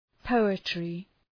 Προφορά
{‘pəʋıtrı}